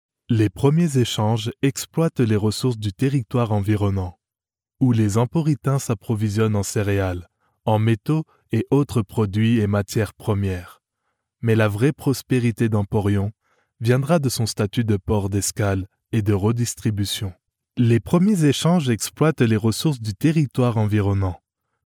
French voice over